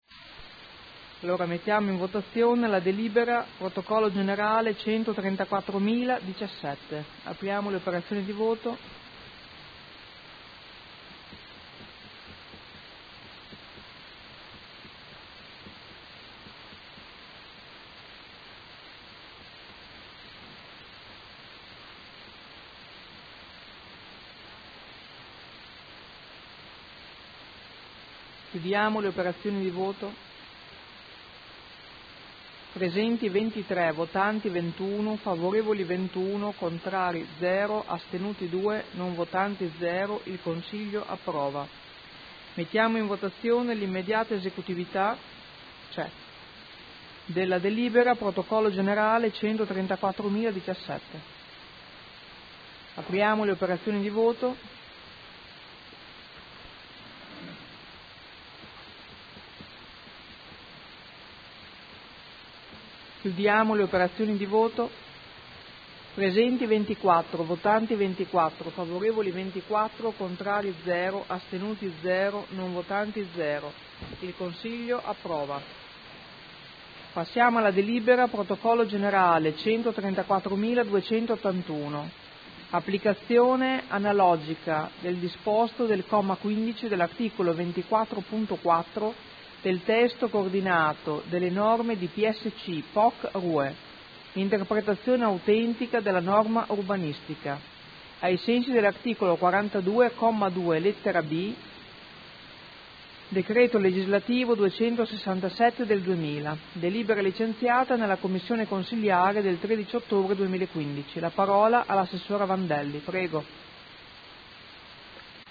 Presidente — Sito Audio Consiglio Comunale
Presidente